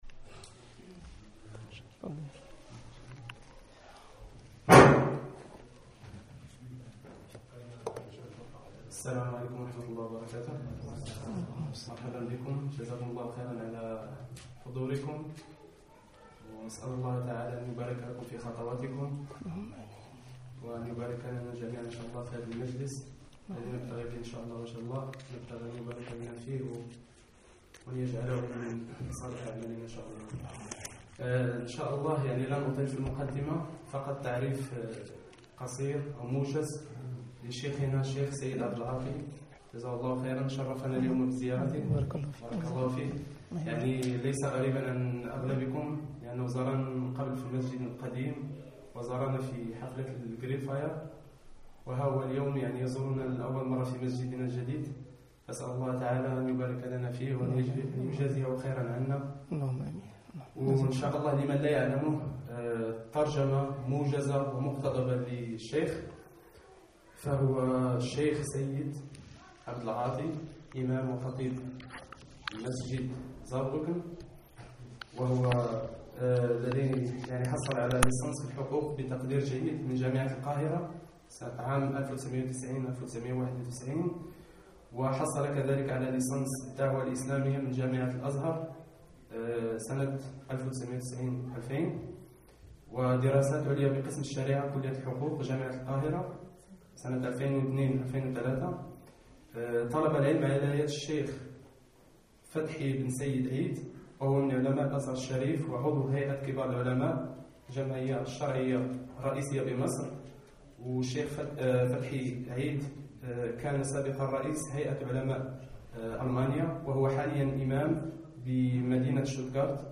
Kaiserslautern_Addaawa ila manhej ahla assunna wa jamaa1_kawaed w usul1.mp3